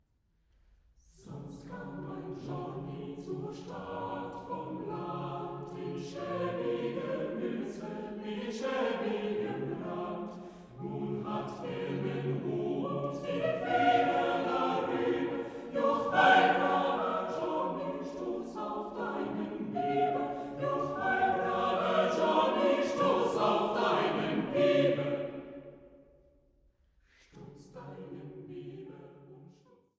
Komposition für gemischten Chor